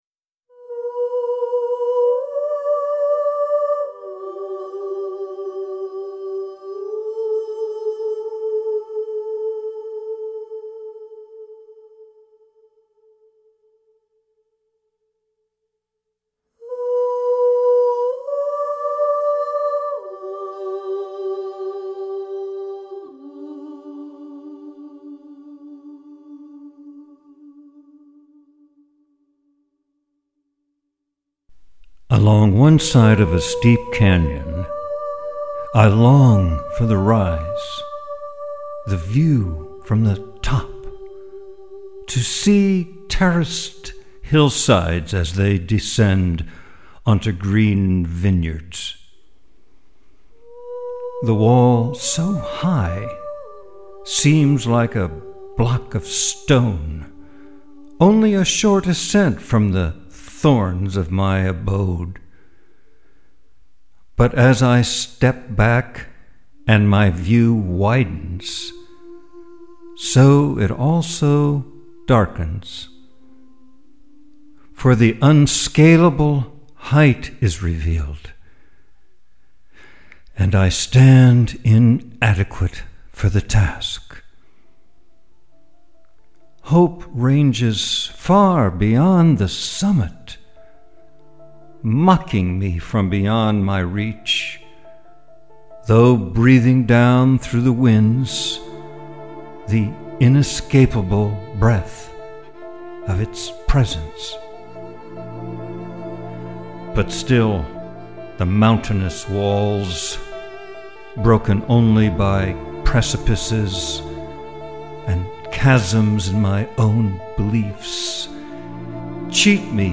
Hope-with-music.mp3